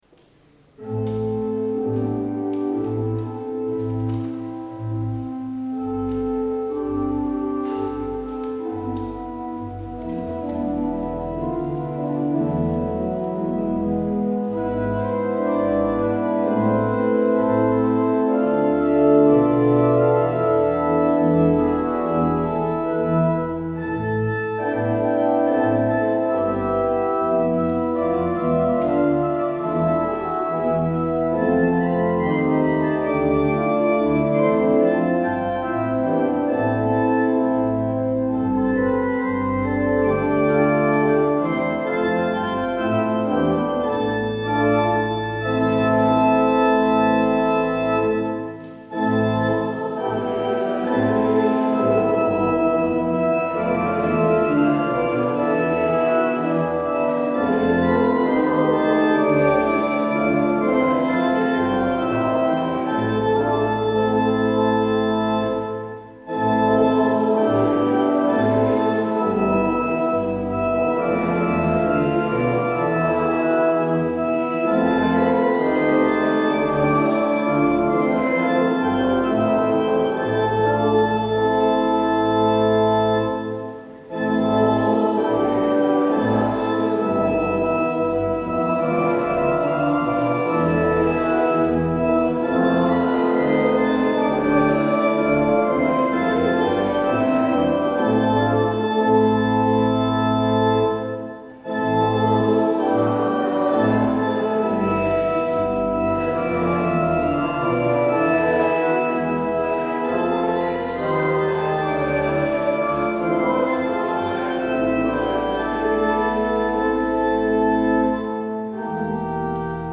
 Luister deze kerkdienst hier terug